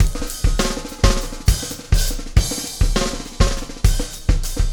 Extra Terrestrial Beat 01.wav